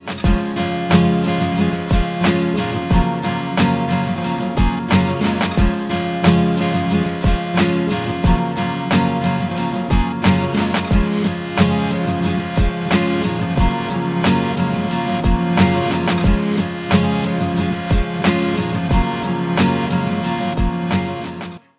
Мелодии на звонок